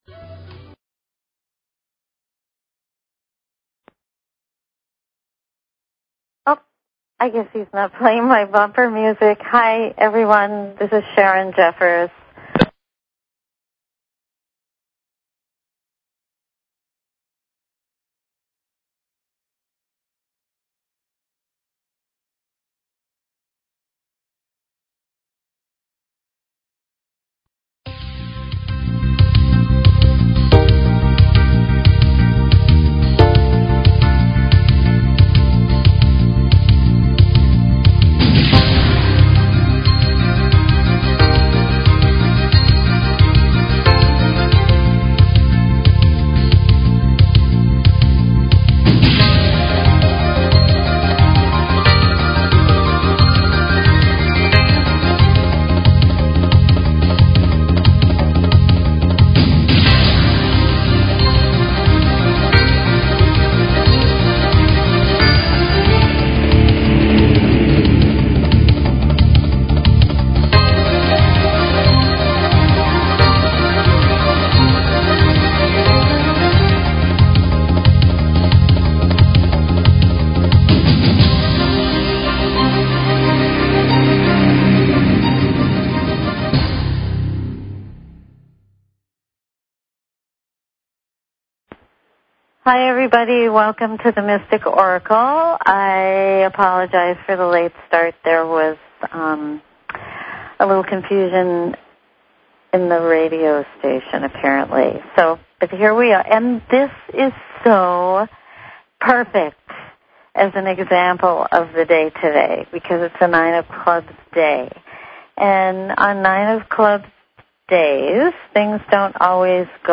Talk Show Episode, Audio Podcast, The_Mystic_Oracle and Courtesy of BBS Radio on , show guests , about , categorized as
Discover the secrets hidden in your birthday, your relationship connections, and your life path. Open lines for calls.